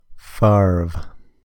Brett Lorenzo Favre (/fɑːrv/
FARV; born October 10, 1969) is an American former professional football quarterback who played in the National Football League (NFL) for 20 seasons, primarily with the Green Bay Packers.
En-ca-favre.ogg.mp3